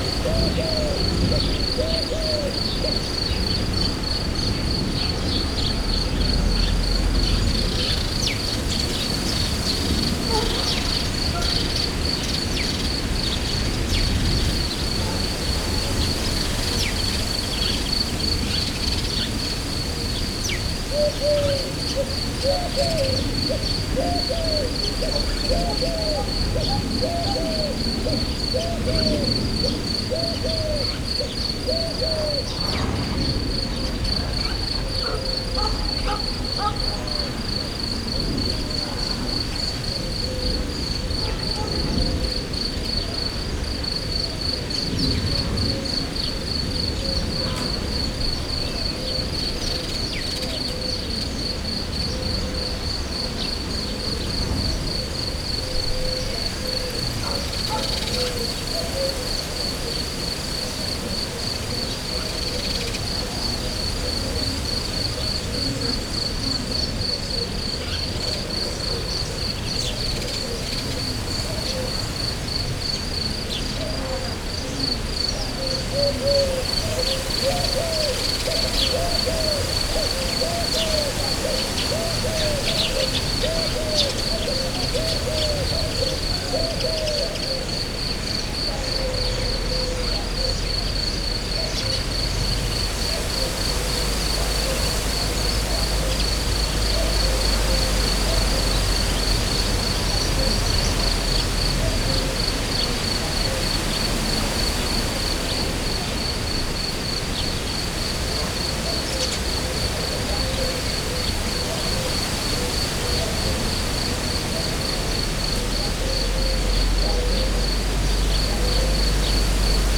gerleesfecskeis_joszelhang_hortobagy_sds02.12.WAV